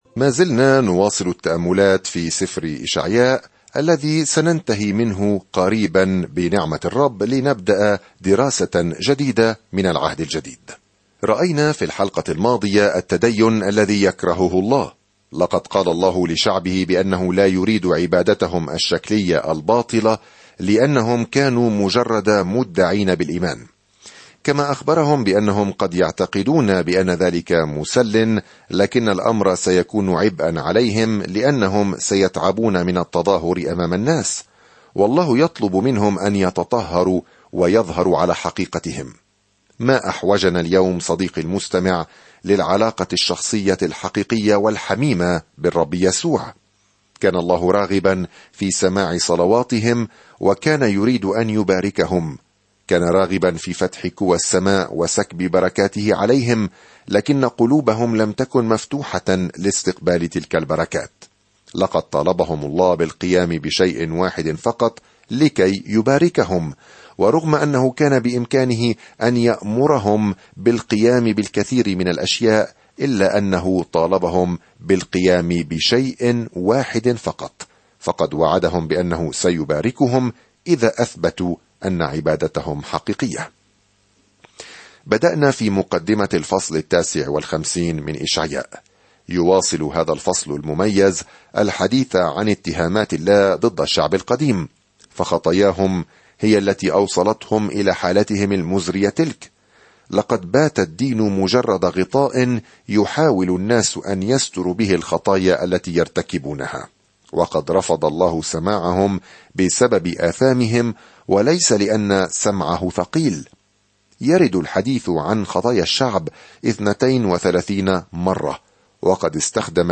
سافر يوميًا عبر إشعياء وأنت تستمع إلى الدراسة الصوتية وتقرأ آيات مختارة من كلمة الله.